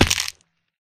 Sound / Minecraft / damage / fallbig1